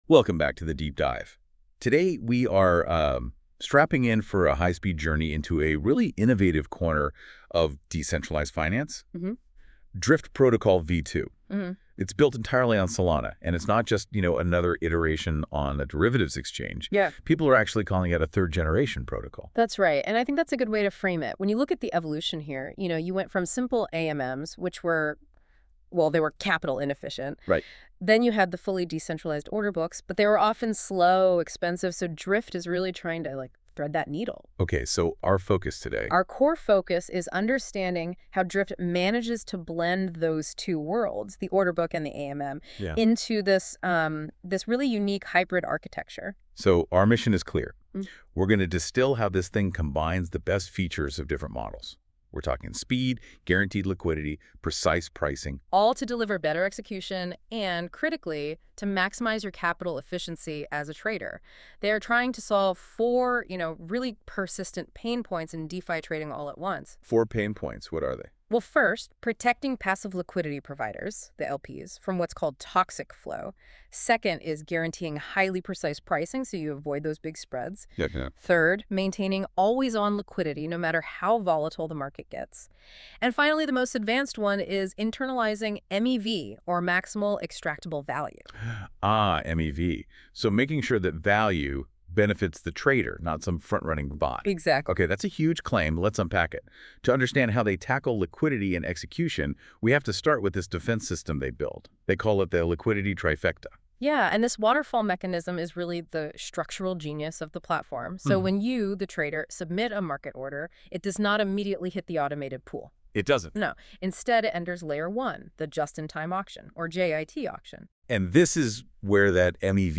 hashtag🎧 Lesson Podcast